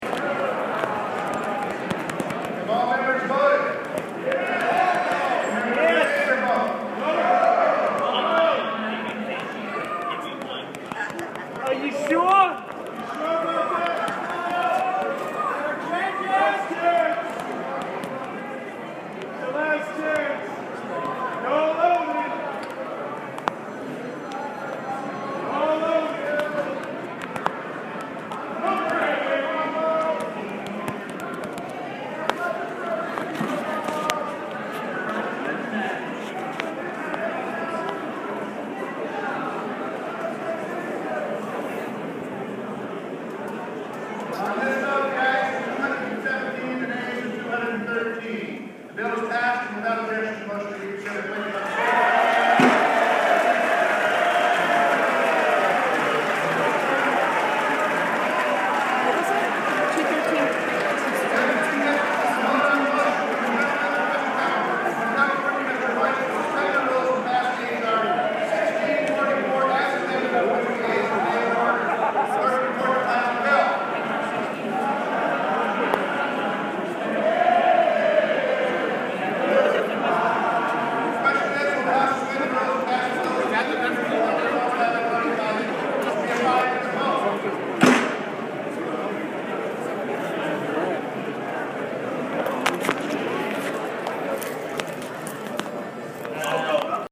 Listen to Democrats' taunts and Republicans' cheers as health care bill passes
The final call for votes is issued. Democrats loudly respond, mocking Republicans with, “Are you sure?” among other taunts. The gavel falls and Republicans let out a cheer of relief -- one that had been building for eight years.
But the sound of the House of Representatives voting today on the American Health Care Act is a fascinating exception. Photos are not permitted, so NewsHour stood next to the open chamber door and recorded audio.
The gavel falls and Republicans let out a cheer of relief that had been building for eight years. The cheers die and Democrats started standing and waving.